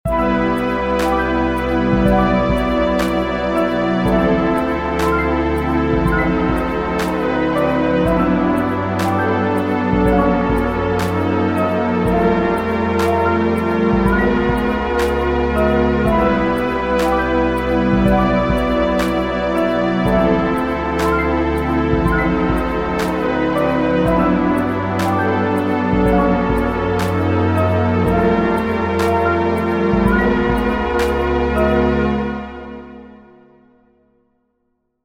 Short 120bpm loop in 19edo, retuned to 17edo
19edo_demo_but_in_17edo.mp3